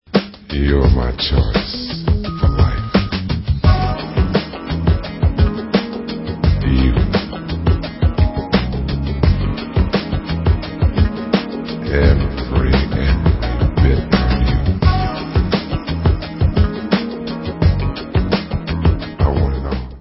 sledovat novinky v oddělení Dance/Soul